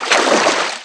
STEPS Water, Stride 03.wav